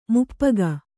♪ muppuga